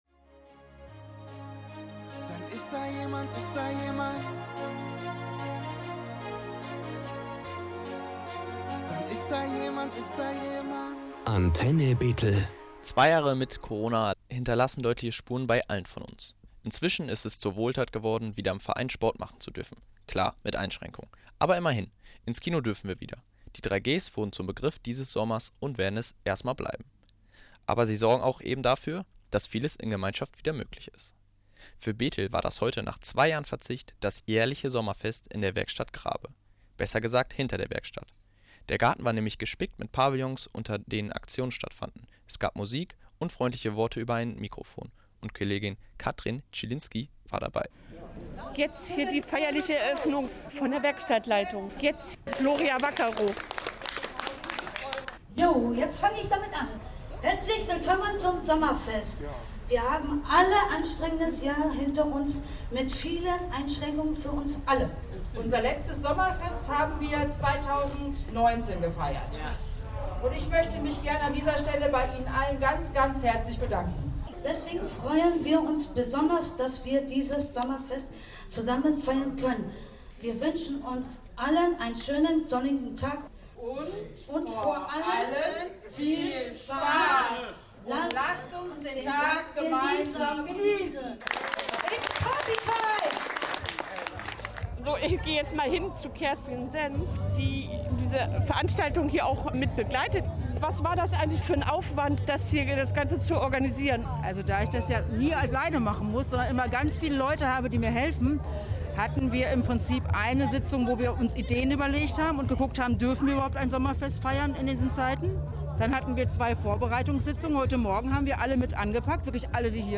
Sommerfest in der Betheler Werkstatt Grabe
Es durfte nach 2 Jahren Verzicht endlich wieder ein Sommerfest stattfinden im Garten der Werkstatt Grabe in Brackwede. Antenne Bethel war dabei: